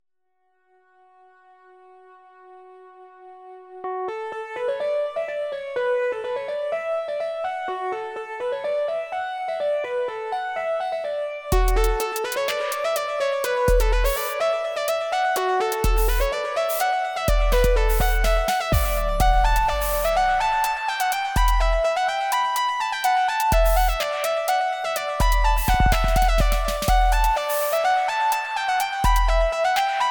“Lo-Fi Cybertrad”